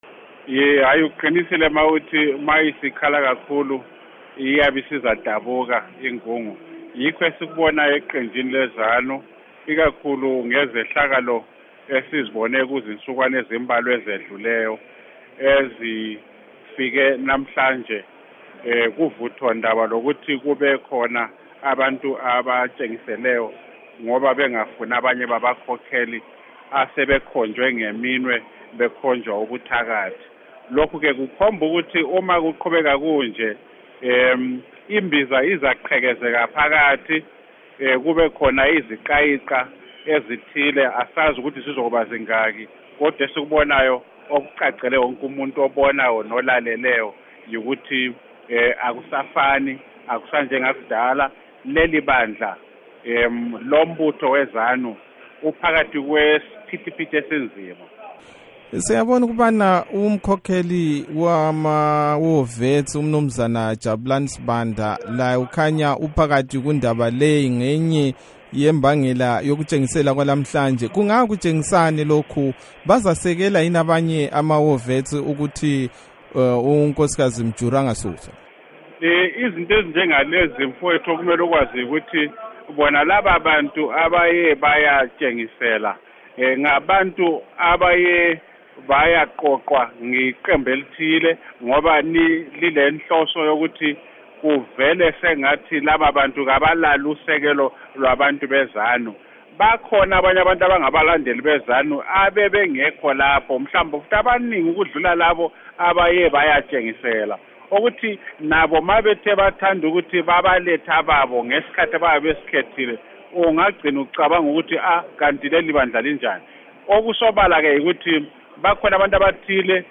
Ingoxoxo